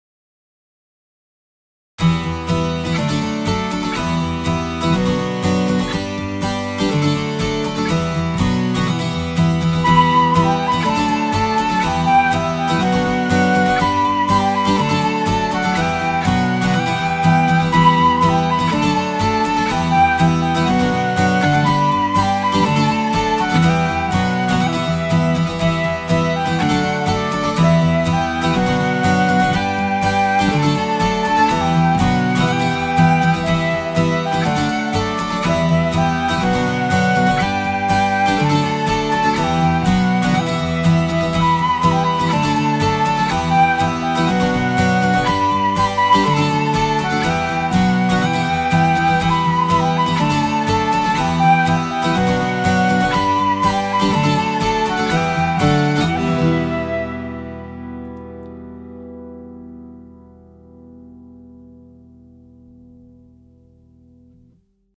InstrumentalCOUPLET/REFRAIN